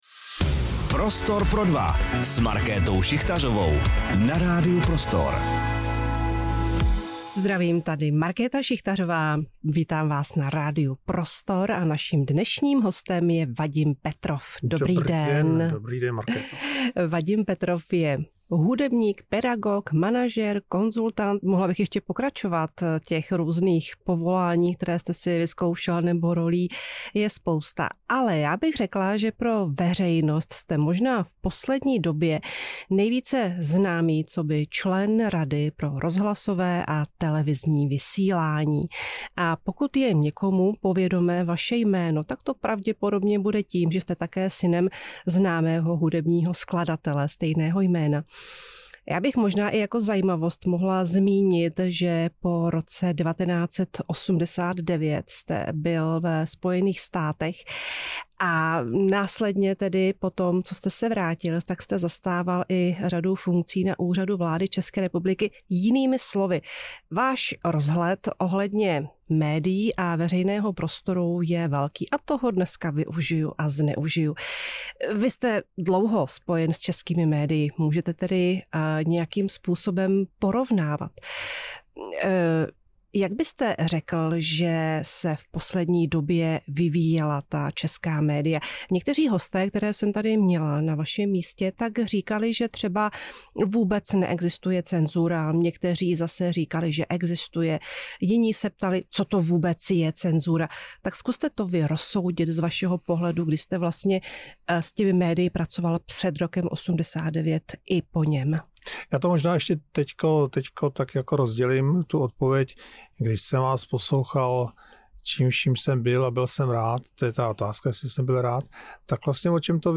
Vadim Petrov, hudebník, pedagog a člen Rady pro rozhlasové a televizní vysílání, se v rozhovoru pro Radio Prostor podělil o svůj názor na současnou mediální krajinu, fenomén cenzury i dezinformací. S Markétou Šichtařovou probírali to, jak média fungují nejen v Česku, ale také ve světovém kontextu.